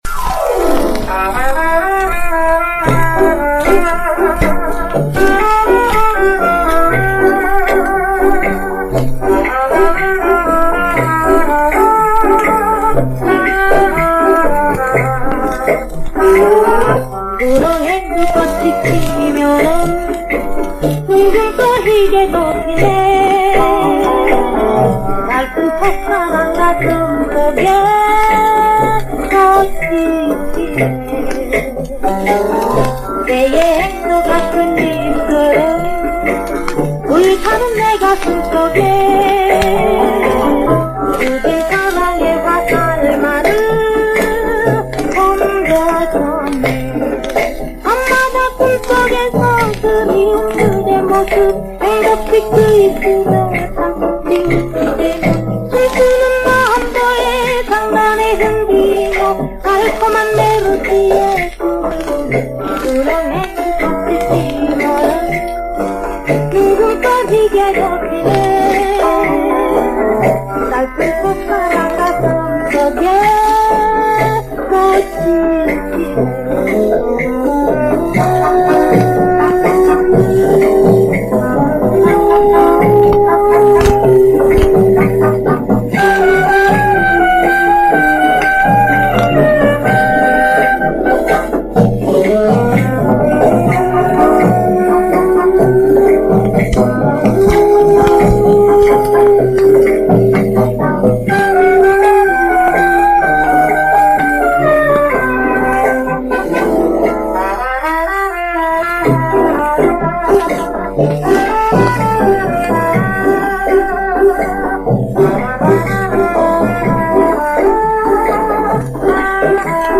♠그때 그 시절 옛 가요/★50~60년(측음기)